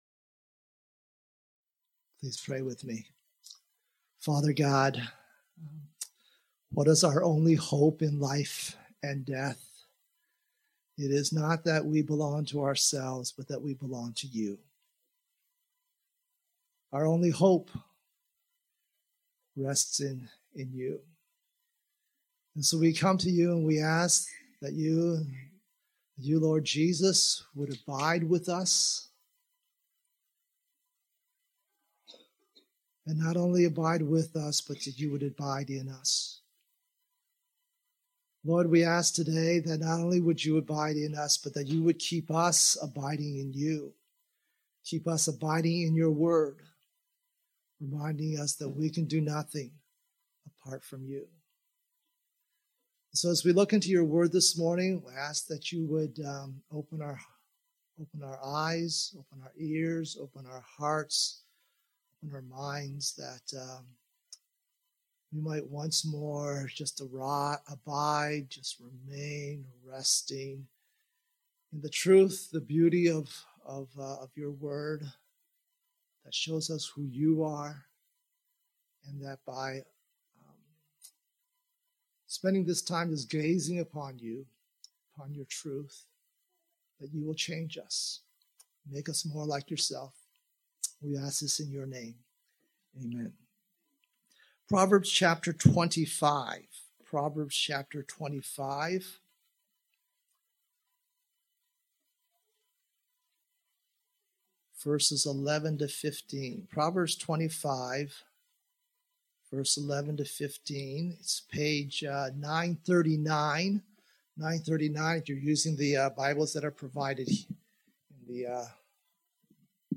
Grace Bible Church of Windsor » Right Words, Right Circumstances